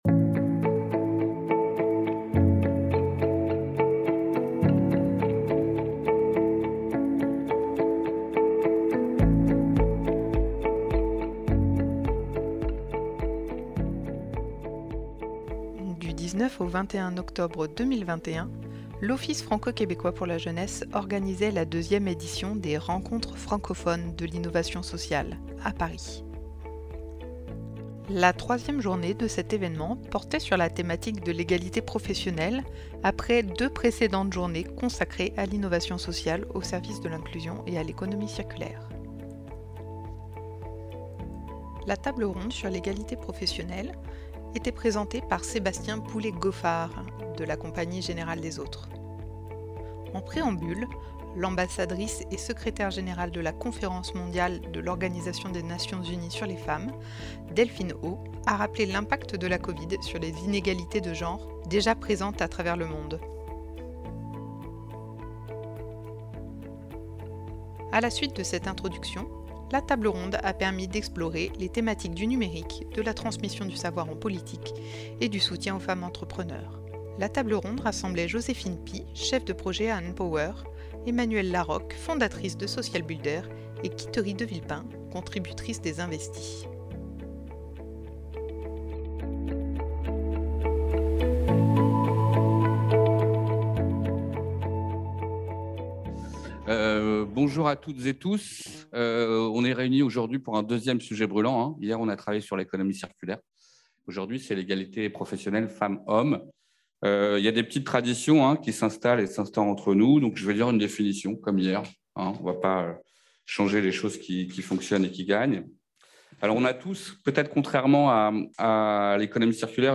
8 mars 2022 - Retour sur la table-ronde sur l'égalité professionnelle dans le cadre des Rencontres francophones de l'innovation sociale 2021.
Table ronde sur l'égalité professionnelle - 1h38min
Table-ronde_EgalitePro_2021.mp3